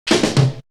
Break 23.wav